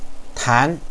tán
tan2.wav